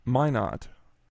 Minot (/ˈmnɒt/
MY-not) is a city in and the county seat of Ward County, North Dakota, United States,[8] in the state's north-central region.
Minot-nd.ogg.mp3